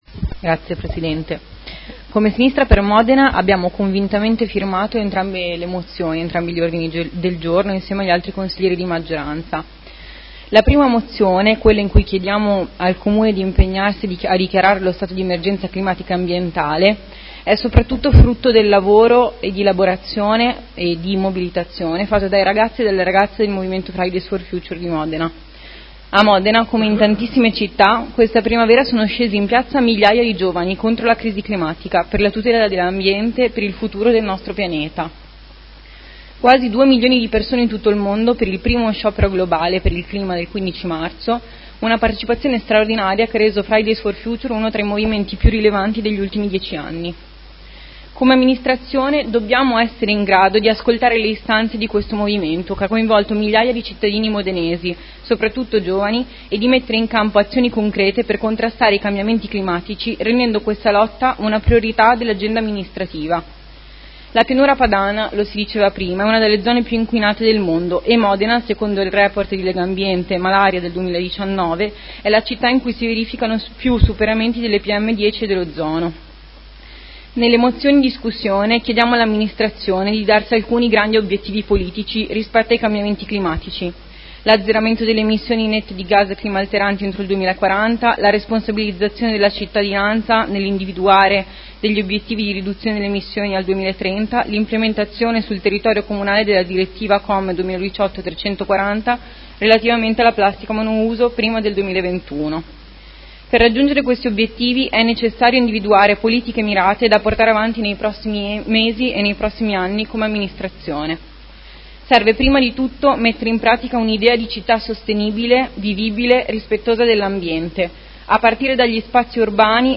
Camilla Scarpa — Sito Audio Consiglio Comunale
Seduta del 25/07/2019 Dibattito. Mozione nr. 187936 - Mozione nr. 221209 ed emendamenti